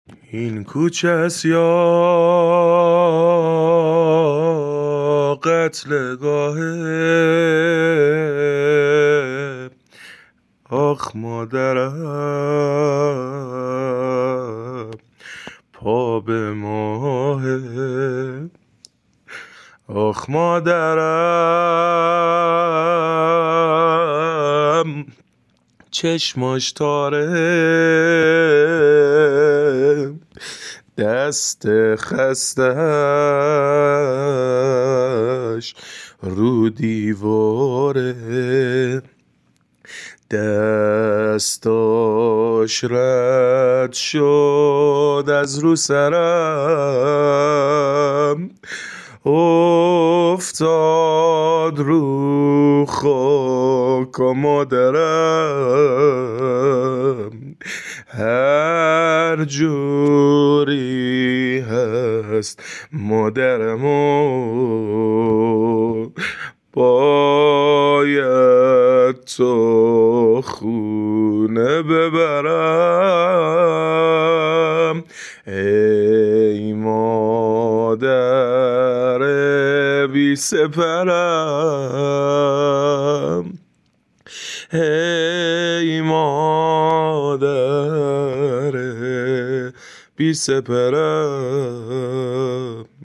عنوان : به این سبک خوانده میشود